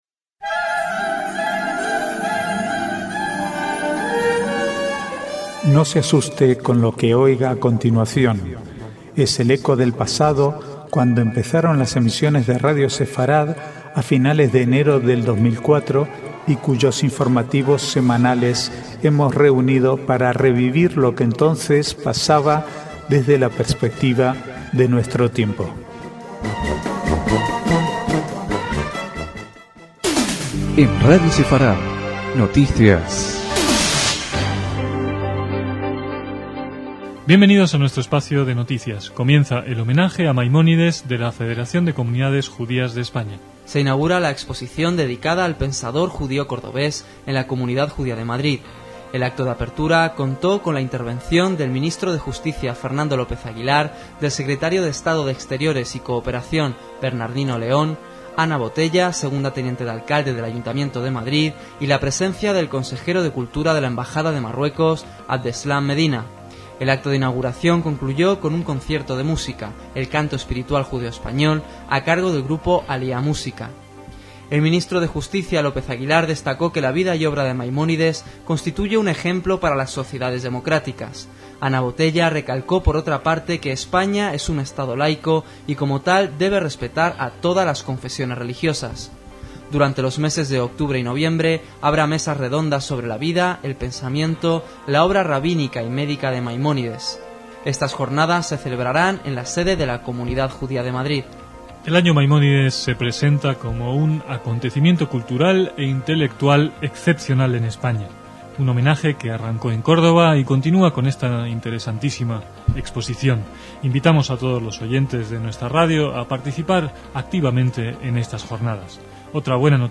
Archivo de noticias del 19 al 22/10/2004